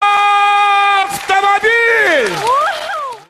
Звук кричащего Якубовича и автомобиля